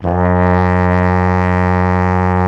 TENORHRN F#0.wav